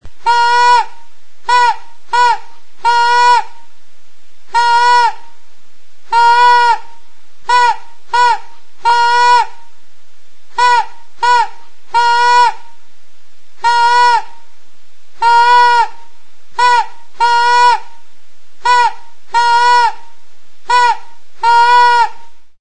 Aerophones -> Reeds -> Single Free
Luzera erditik ebakia dagoen urritza makil bat da. Bi zatien artean gomazko bizikleta neumatiko puska bat tinkaturik du.